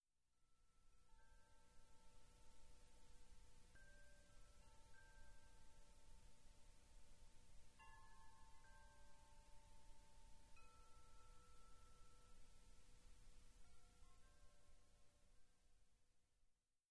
ModChimes produce beautiful, distinct, bell-like tones
C4 E4 G4 B4 D5
C3-E4-G4-B4-D5.mp3